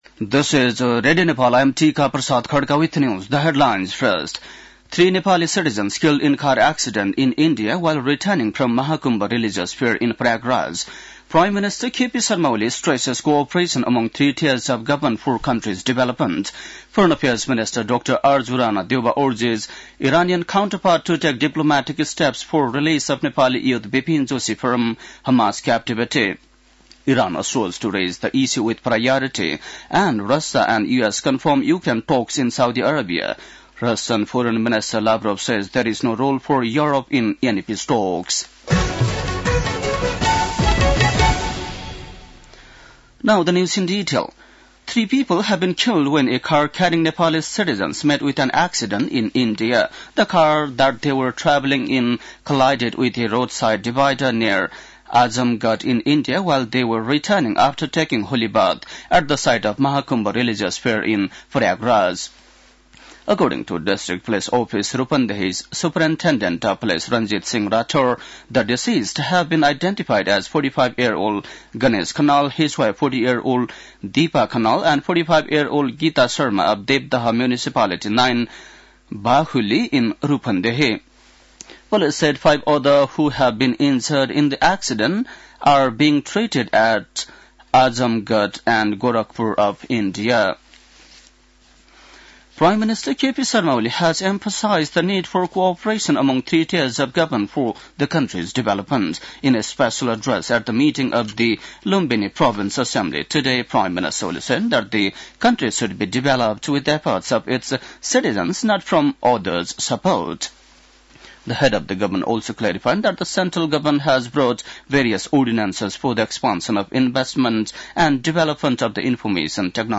बेलुकी ८ बजेको अङ्ग्रेजी समाचार : ६ फागुन , २०८१
8-pm-english-news-11-05.mp3